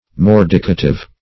Search Result for " mordicative" : The Collaborative International Dictionary of English v.0.48: Mordicative \Mor"di*ca*tive\, a. [L. mordicativus.] Biting; corrosive.